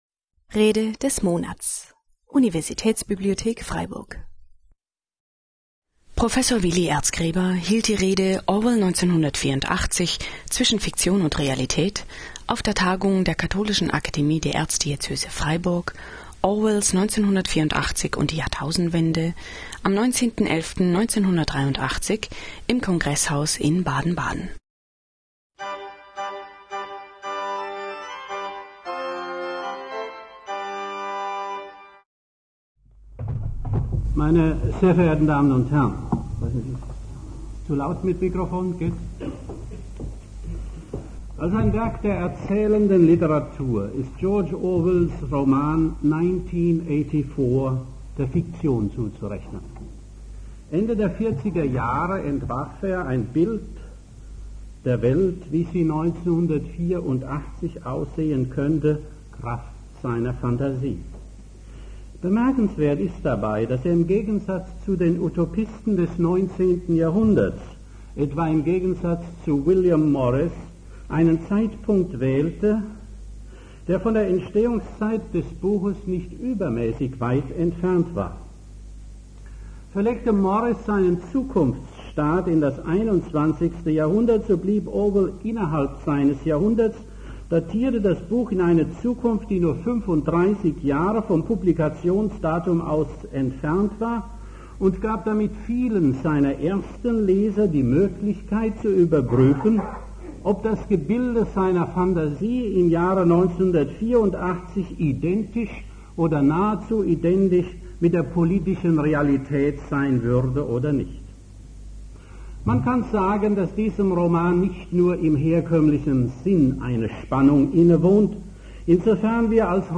Orwell 1984 - Zwischen Fiktion und Realität (1983) - Rede des Monats - Religion und Theologie - Religion und Theologie - Kategorien - Videoportal Universität Freiburg